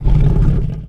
Angry Chimera Growls
tb_growl_3.ogg